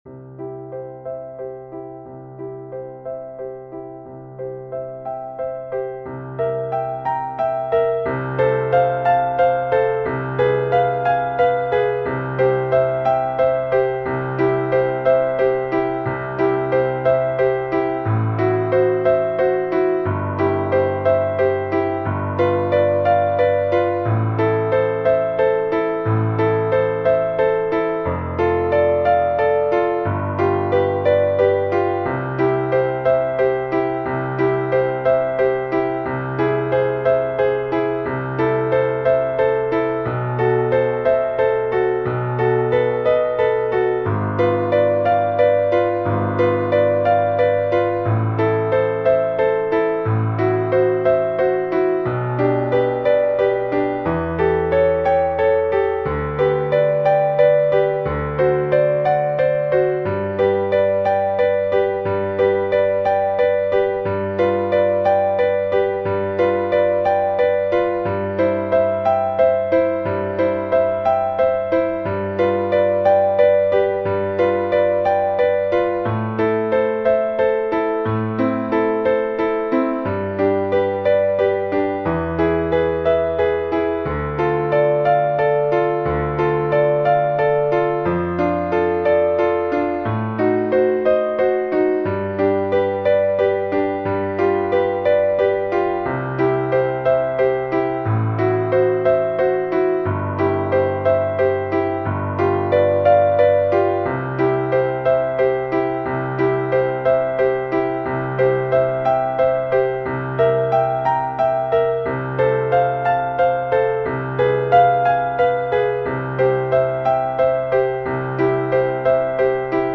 Tonalità: si bemolle maggiore
Metro: 4/4
MP3 (base con pianoforte)
Arrangiamento per violino